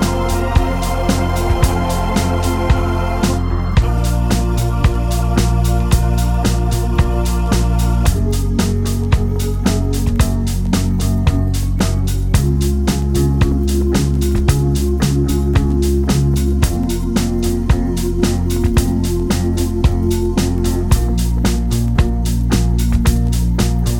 Two Semitones Down Pop (2000s) 2:59 Buy £1.50